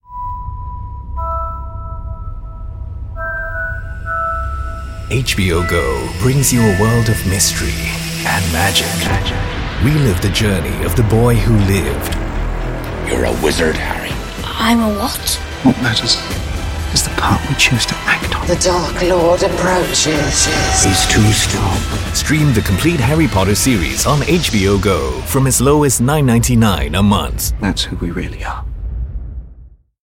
English (Singapore)
Movie Trailers
Baritone
WarmAuthoritativeConversationalFriendlyDarkEngagingAssuredReliable